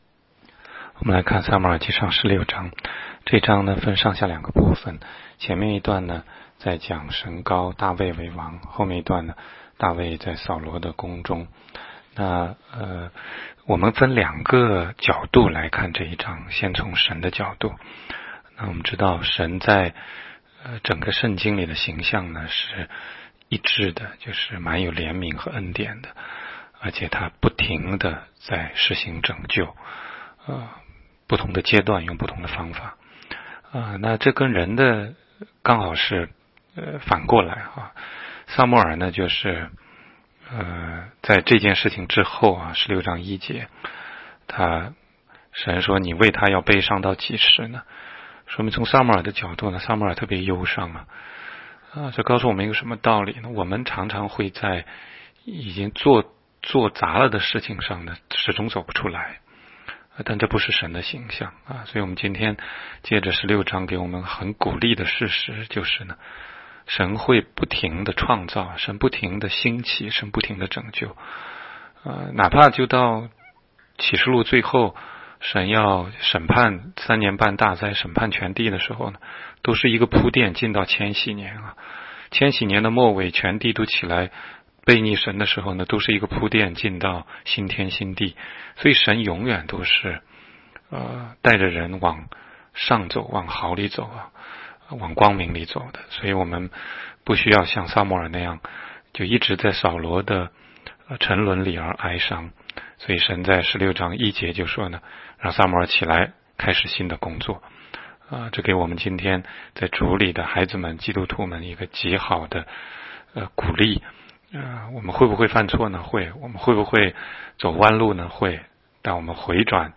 16街讲道录音 - 每日读经-《撒母耳记上》16章